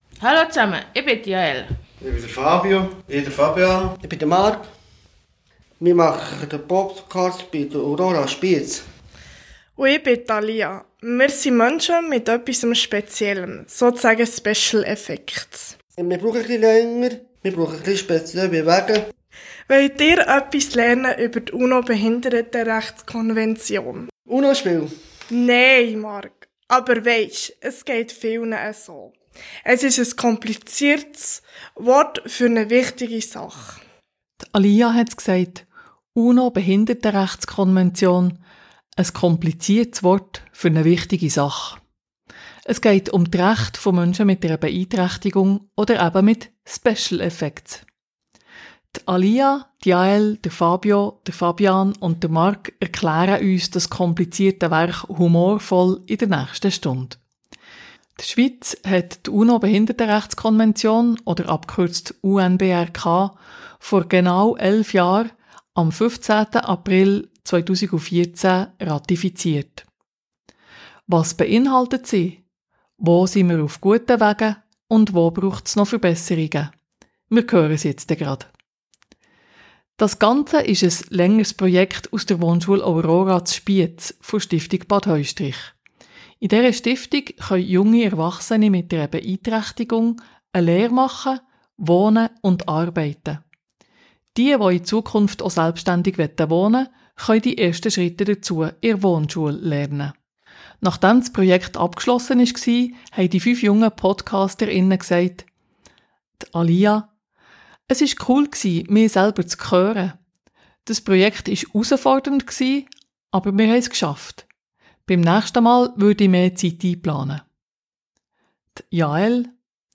11-jähriges Jubiläum UNO-Behindertenrechtskonvention: Es geht nicht um das bekannte Kartenspiel, sondern um Rechte für Menschen mit einer Beeinträchtigung. Junge Menschen mit Beeinträchtigung, mit «special effects» wie sie es selbst sagen, erklären das komplizierte Werk humorvoll in einem Podcast.